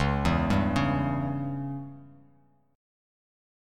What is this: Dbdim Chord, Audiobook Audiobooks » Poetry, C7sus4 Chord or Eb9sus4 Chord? Dbdim Chord